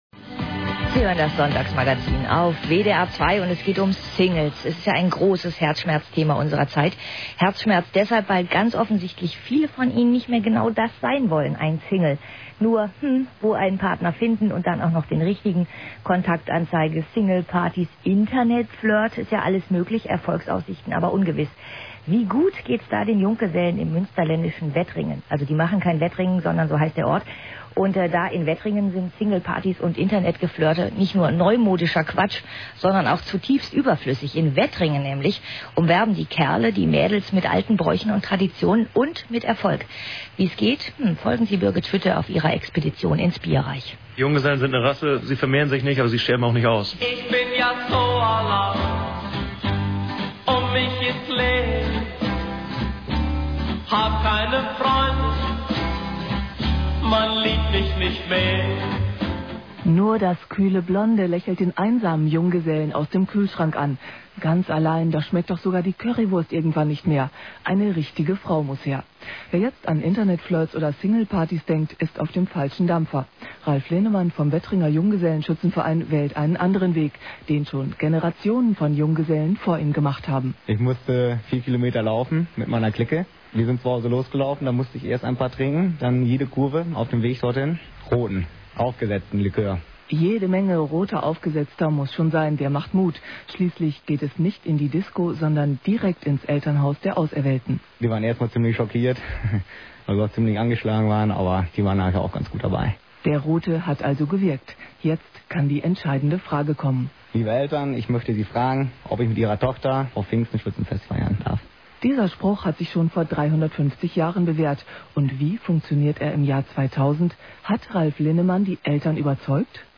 Radiobeitrag (mp3 -mono- 592 kB, 4:23 min)